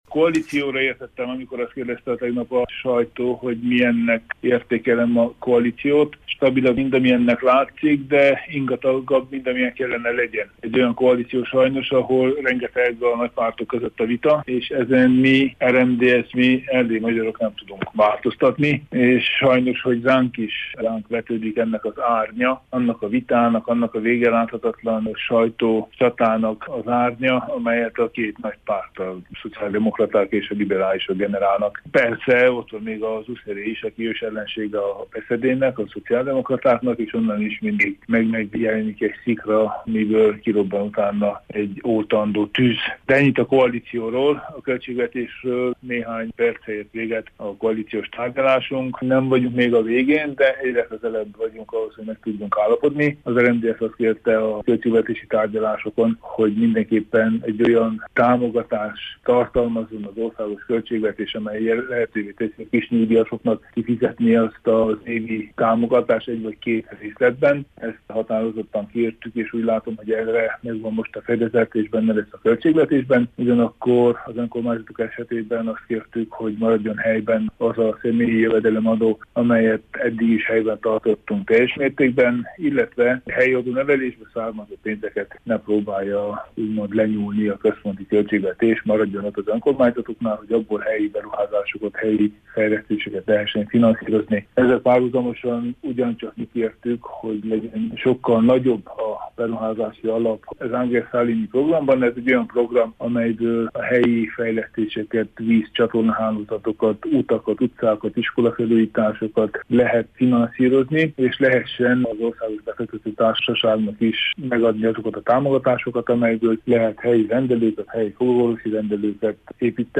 A tervek szerint viszont jövő héten mindenképpen a parlament elé terjesztik a költségvetést, mondta a koalíciós találkozót követően Tánczos Barna miniszerterlnök-helyettes, aki a koalíció stabilitása kapcsán is beszélt rádiónknak.